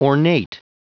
Prononciation du mot ornate en anglais (fichier audio)
Prononciation du mot : ornate